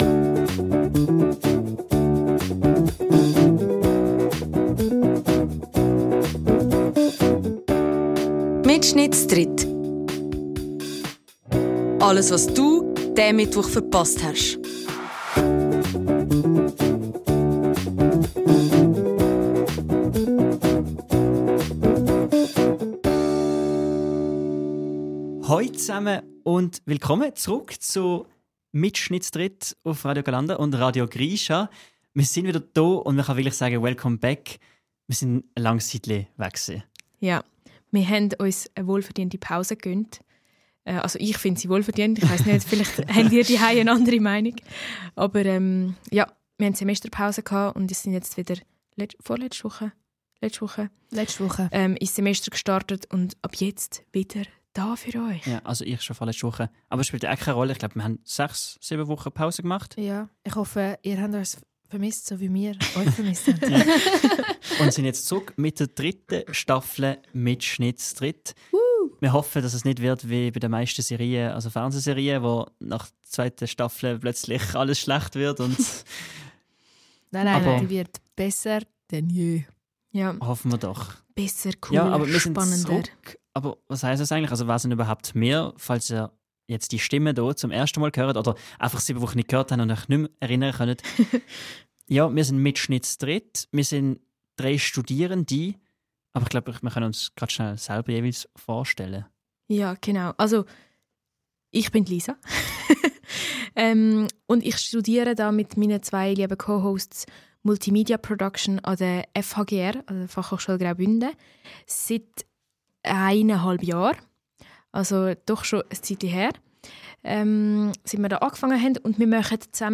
Mir sind zrugg us de Sendepause und startet top motiviert ih die dritti Staffle. Das mal gids nöd nur es spannend Interview mit emene Iihblick id Produktion vomene ESC Event, sondern au bi eusne altbekannte Format gits öppis z'lerne, philosphiere und rätsle.